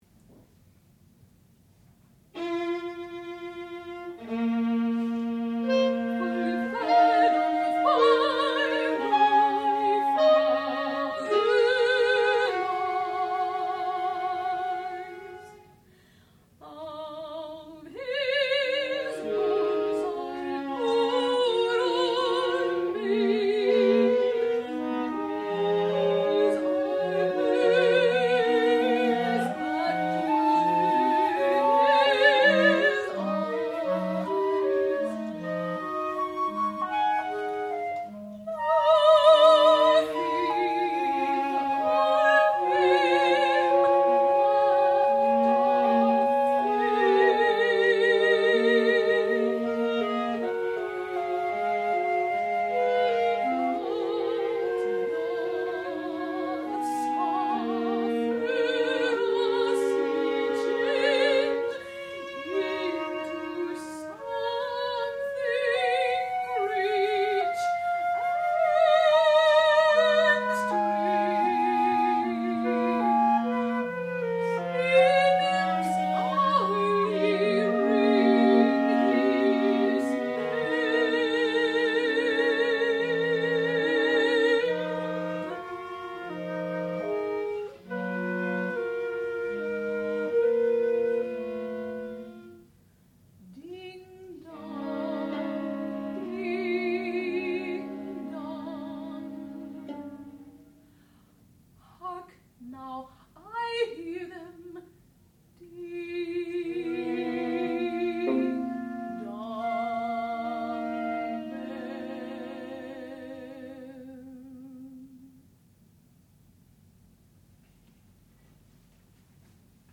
sound recording-musical
classical music
clarinet
flute
viola